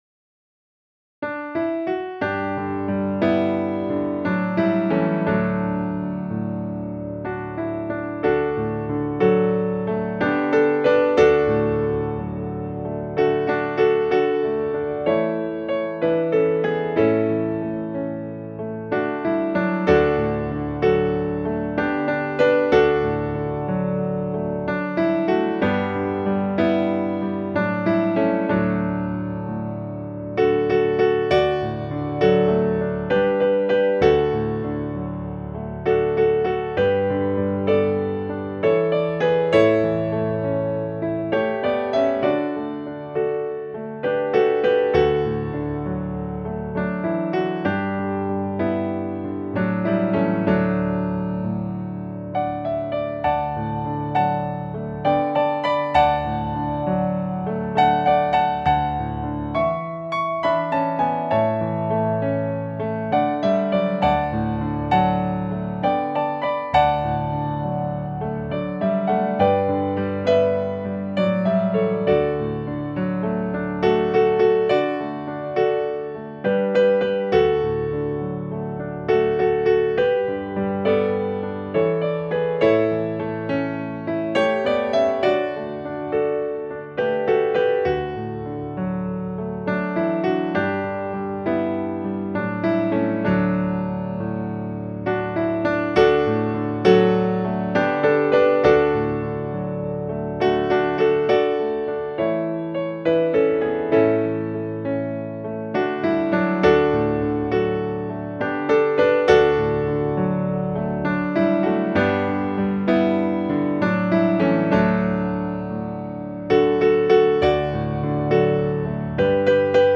Traditional Hymns